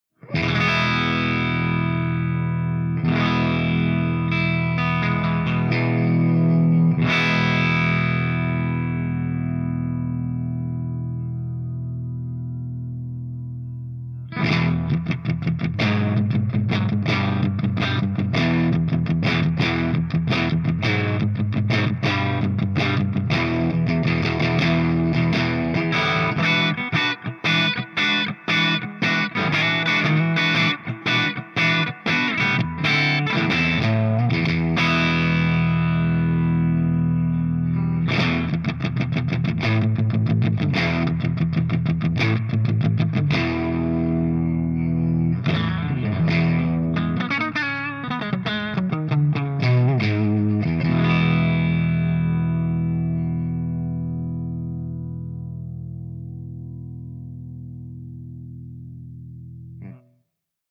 100_HIWATT_HIGHDRIVE_GB_HB.mp3